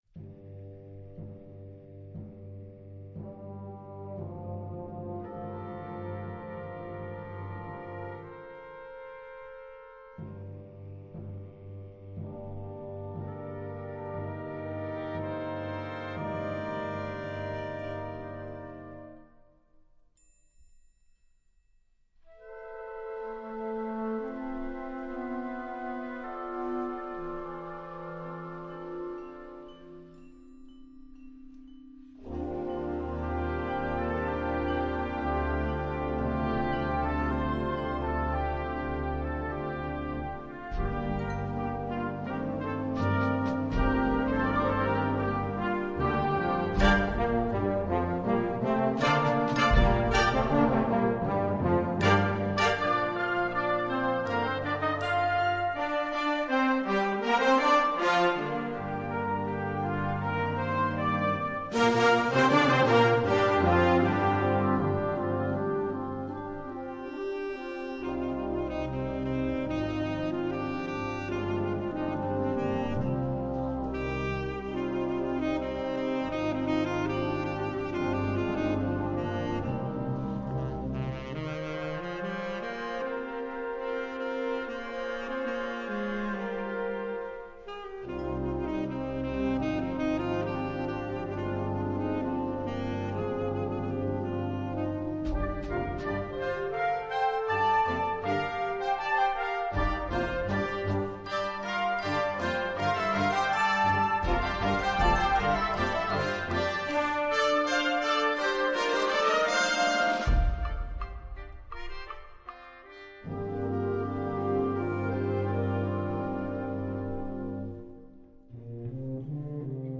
Gattung: Konzertante Blasmusik
5:45 Minuten Besetzung: Blasorchester Tonprobe